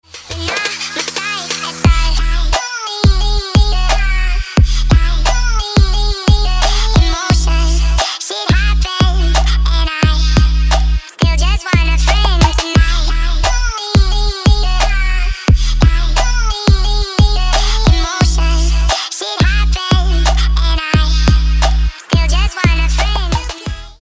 • Качество: 320, Stereo
dance
Electronic
Trap
club